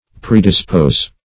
Predispose \Pre`dis*pose"\, v. t. [imp. & p. p. Predisposed;